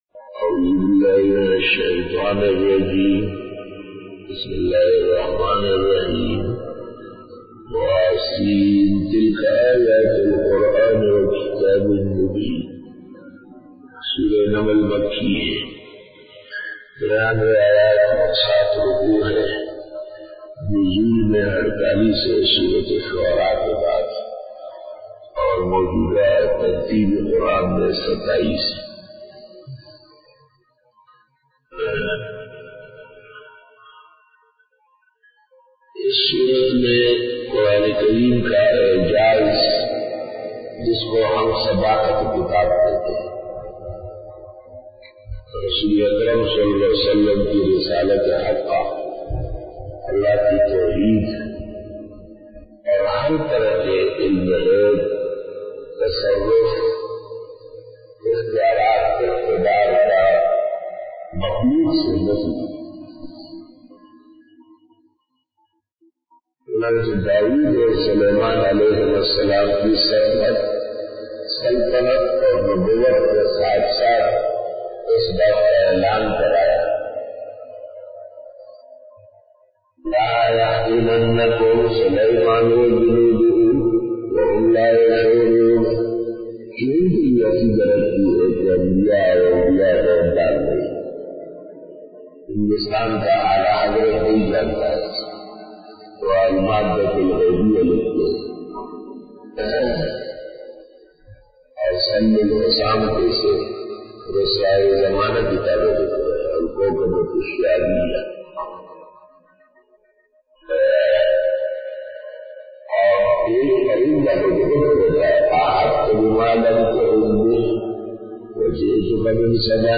دورہ تفسیر | دن 15 |2014 Bayan